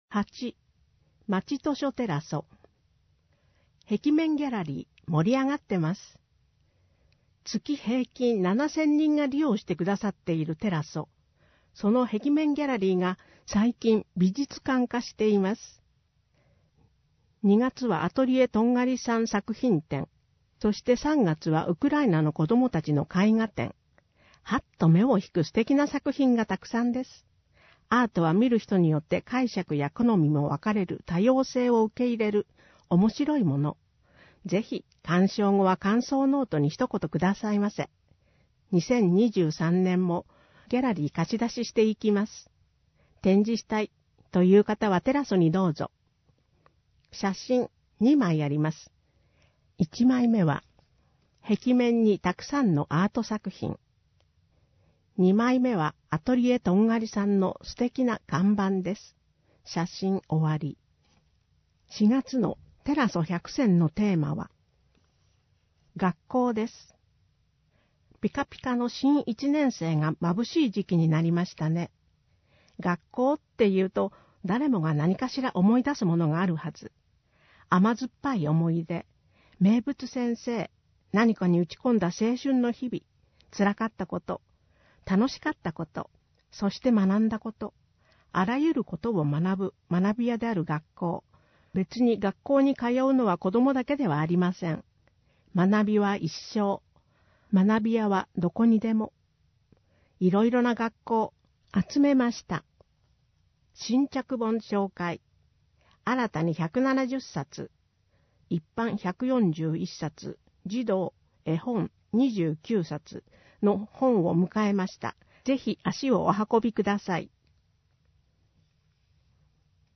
毎月発行している小布施町の広報紙「町報おぶせ」の記事を、音声でお伝えする（音訳）サービスを行っています。音訳は、ボランティアグループ そよ風の会の皆さんです。